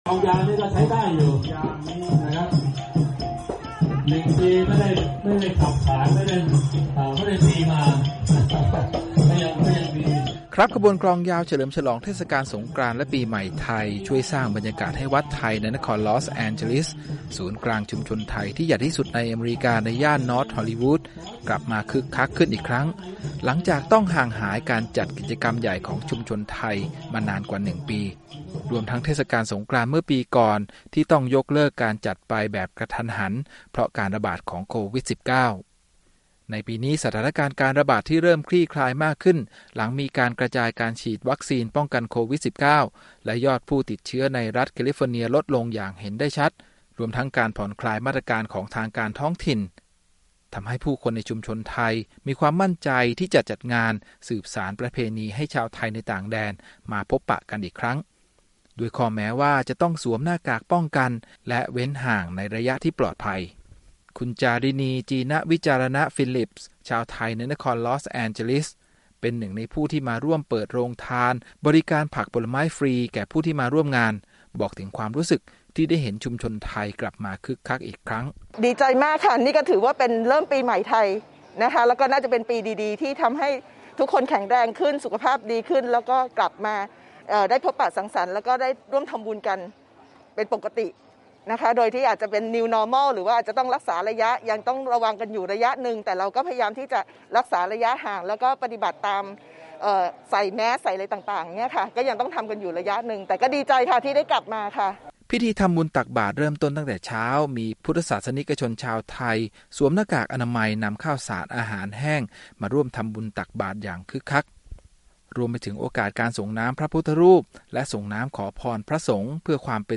ขบวนกลองยาวเฉลิมฉลองเทศกาลสงกรานต์ และปีใหม่ไทย ช่วยสร้างบรรยากาศให้วัดไทยในนครลอสแอนเจลิส ศูนย์กลางของชุมชนไทยที่ใหญ่ที่สุดในอเมริกา ในย่านนอร์ท ฮอลลีวูด กลับมาคึกคักขึ้นอีกครั้ง หลังจากต้องห่างหายการจัดกิจกรรมใหญ่ของชุมชนมานานกว่า 1 ปี รวมทั้งงานเทศกาลสงกรานต์เมื่อปีก่อนที่ต้องยกเลิกการจัดไปเพราะการระบาดของโควิด-19